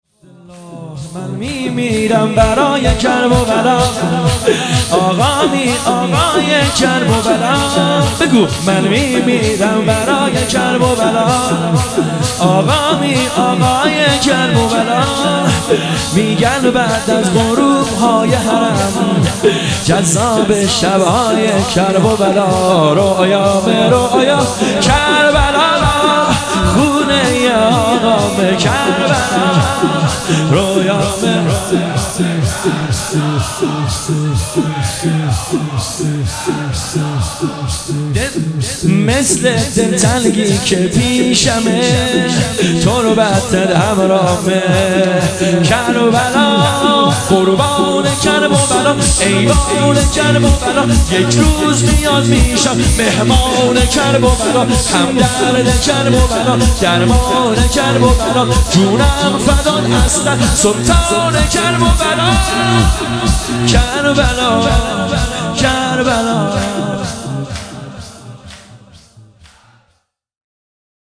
شور - مجلس روضه فاطمیه